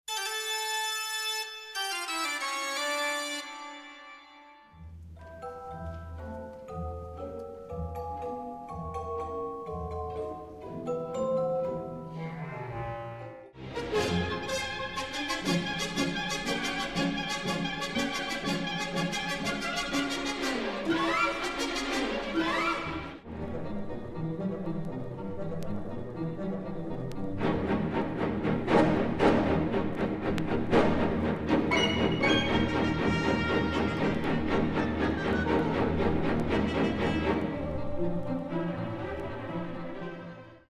В подборке процитированы: "Токката и фуга ре минор" Баха, "Танец феи Драже" Чайковского, "Ученик чародея" Поля Дюка и "Весна священная" Стравинского. Из подборки торчат уши Микки Мауса, поскольку эти произведения легли в основу полнометражного мультфильма "Фантазия" (1940).